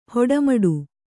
♪ haoda maḍu